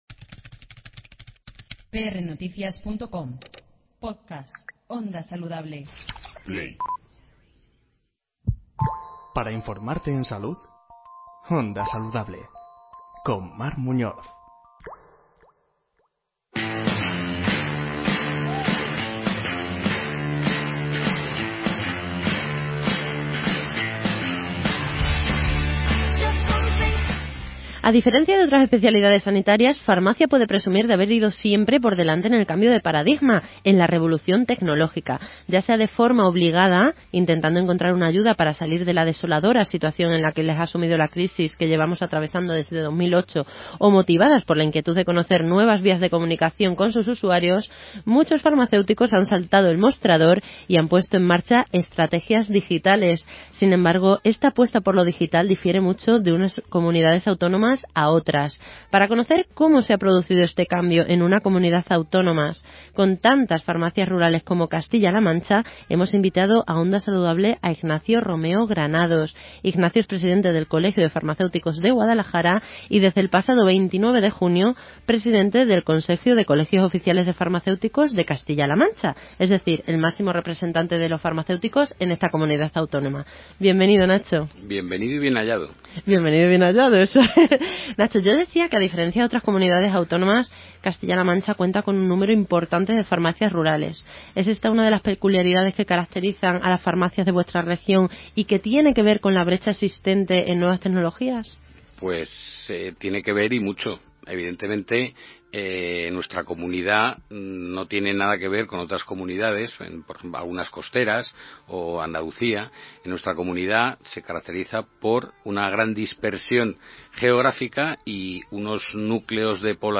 En una entrevista en OndaSaludable